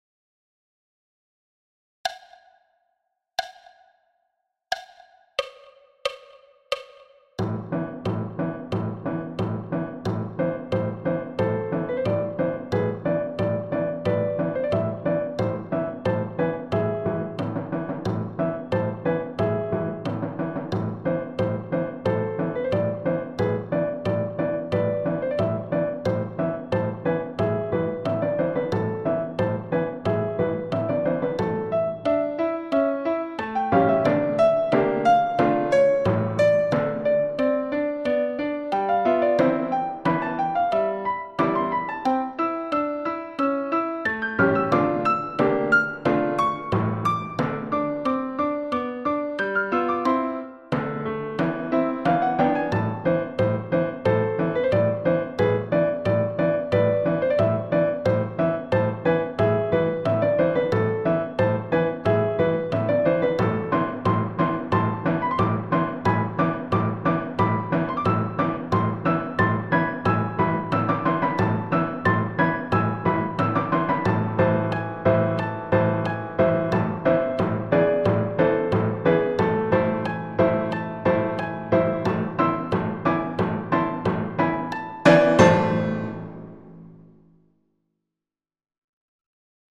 Le petit cygne – piano solo à 90 bpm